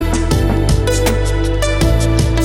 Local radio announcements